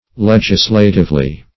Meaning of legislatively. legislatively synonyms, pronunciation, spelling and more from Free Dictionary.
Search Result for " legislatively" : Wordnet 3.0 ADVERB (1) 1. by legislation ; - Example: "legislatively determined" The Collaborative International Dictionary of English v.0.48: Legislatively \Leg"is*la*tive*ly\, adv. In a legislative manner.